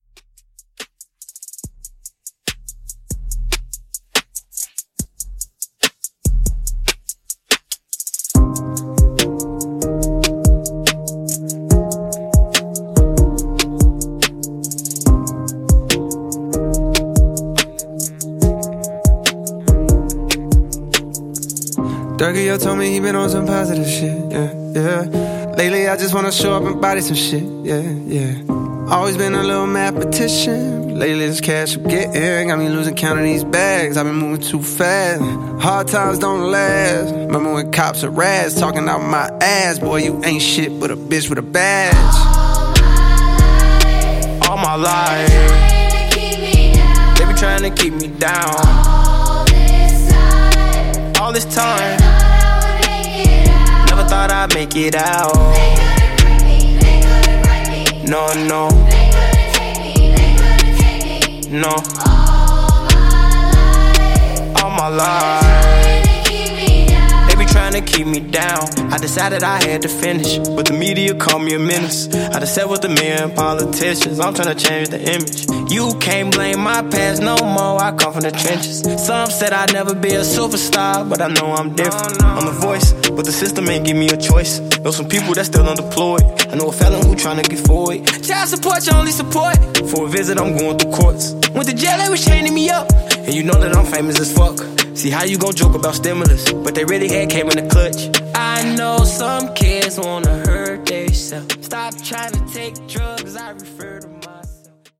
Genre: MASHUPS
Clean BPM: 106 Time